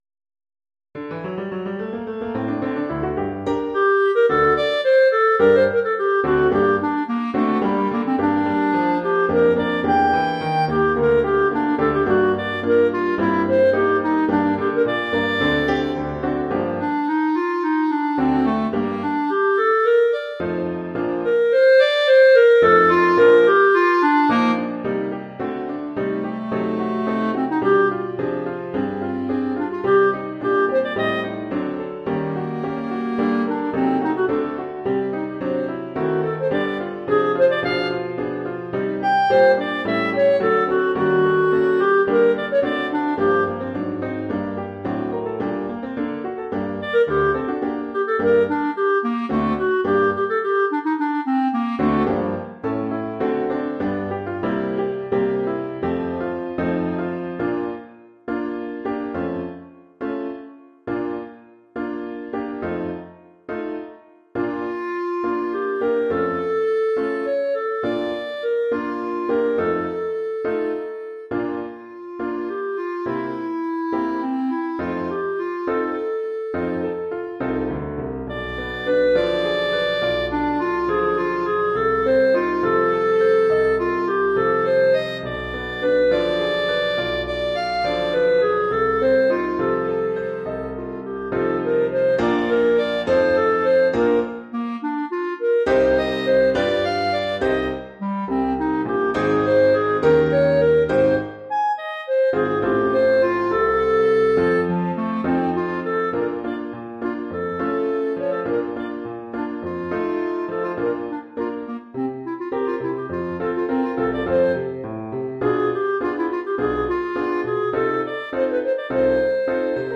Formule instrumentale : Clarinette et piano
Oeuvre pour clarinette et piano.
avec de riches harmonies et des mélodies flamboyantes